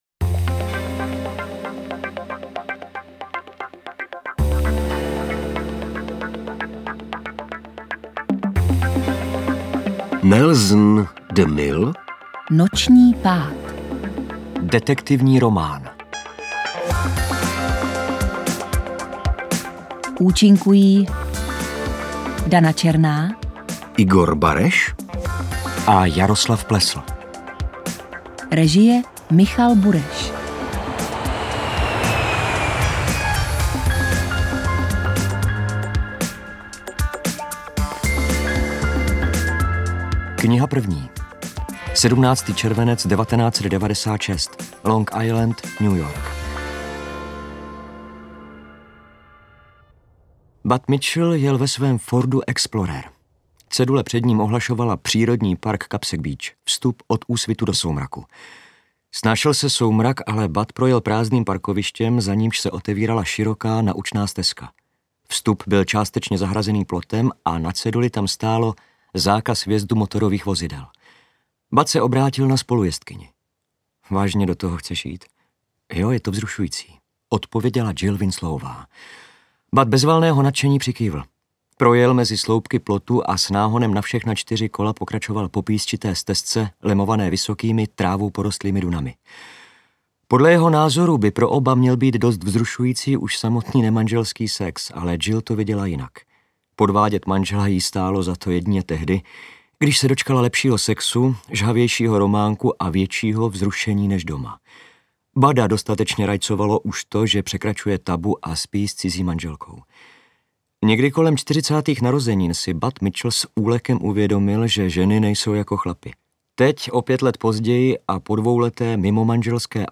Interpreti:  Igor Bareš, Jaroslav Plesl
AudioKniha ke stažení, 19 x mp3, délka 6 hod. 31 min., velikost 536,2 MB, česky